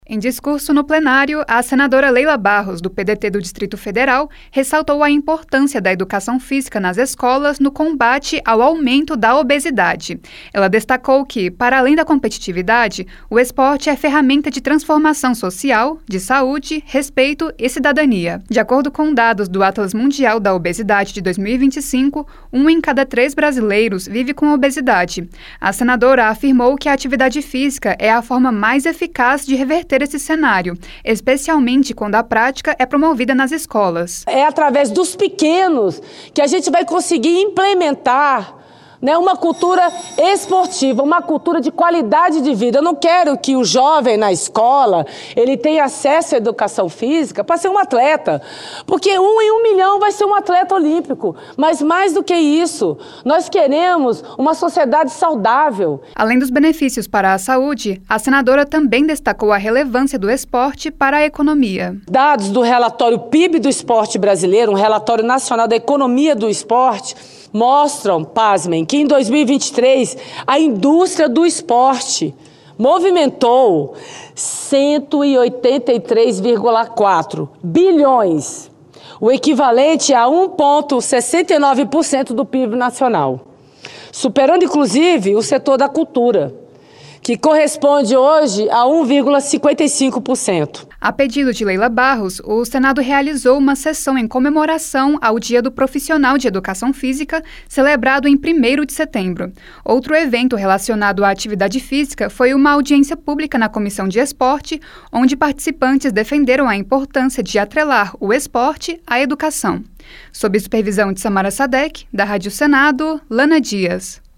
A senadora Leila Barros (PDT-DF) defendeu a prática de educação física nas escolas como forma de combater a obesidade, em discurso no Plénario na quarta-feira (3).